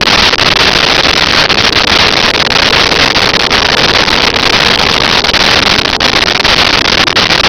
Sfx Amb Wind Tat A Loop
sfx_amb_wind_tat_a_loop.wav